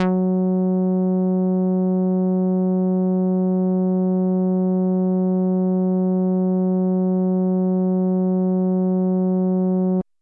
标签： midivelocity48 F4 midinote66 RolandJX8P synthesizer singlenote multisample
声道立体声